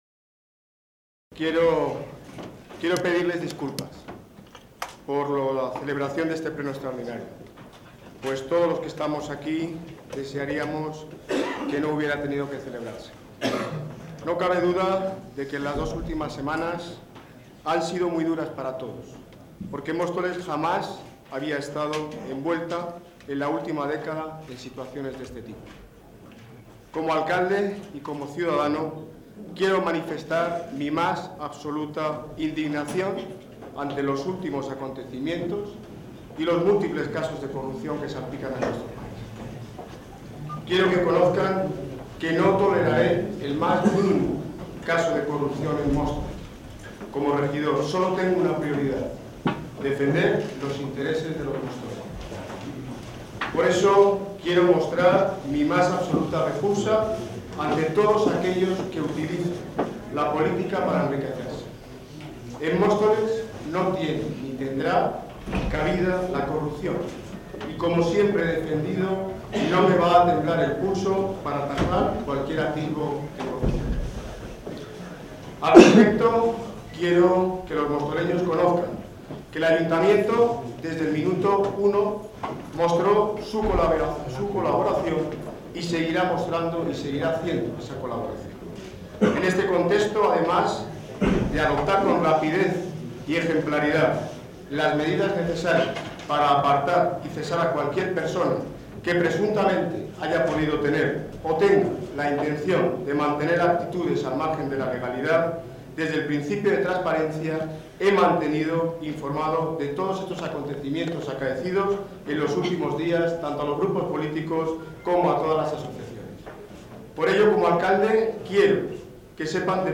Audio - Intervención íntegra Daniel Ortiz (Alcalde de Móstoles) sobre últimos acontecimientos